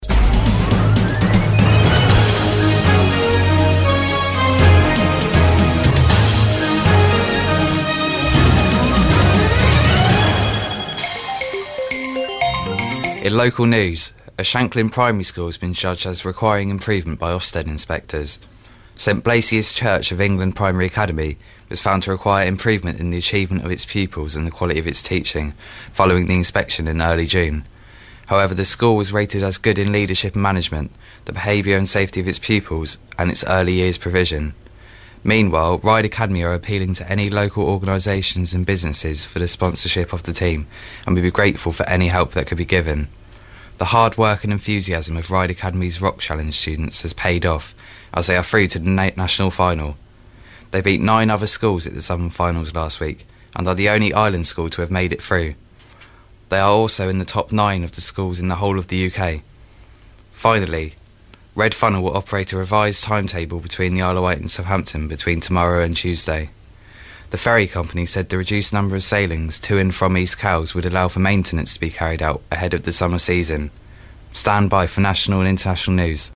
Reads the News